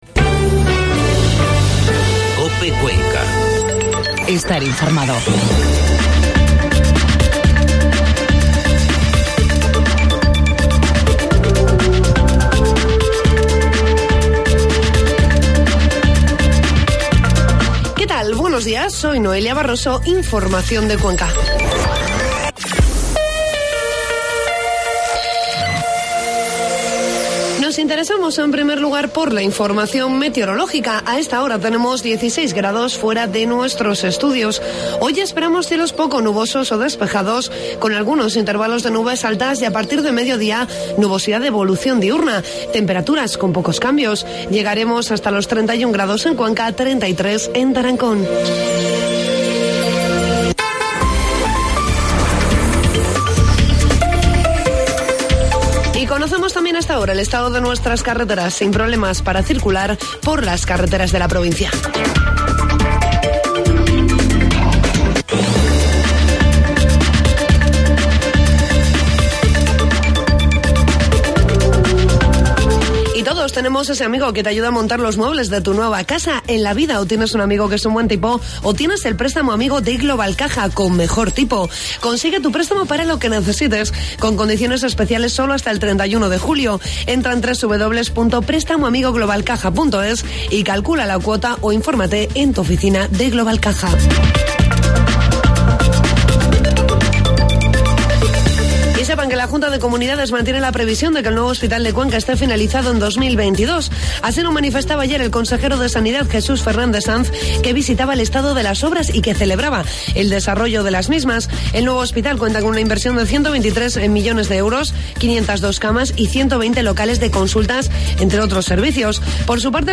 AUDIO: Informativo matinal 19 de junio